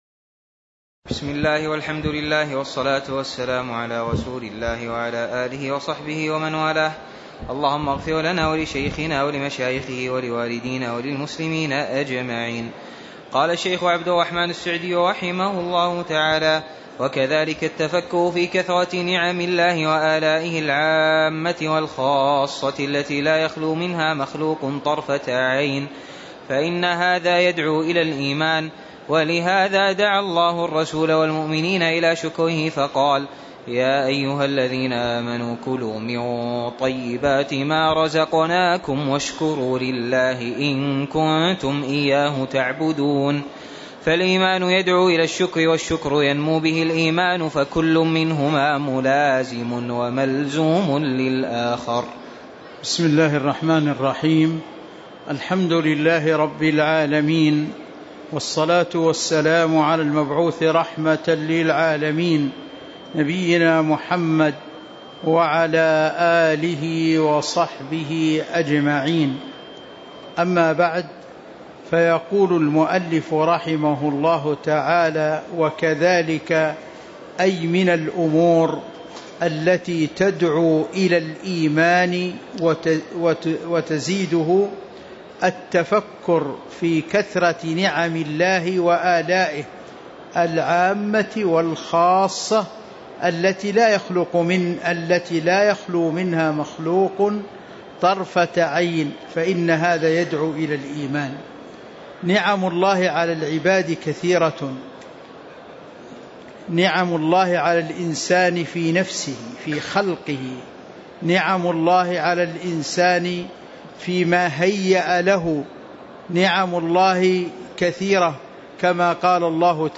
تاريخ النشر ٢٨ ربيع الثاني ١٤٤٥ هـ المكان: المسجد النبوي الشيخ